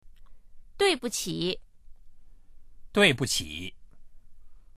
duibuqi.mp3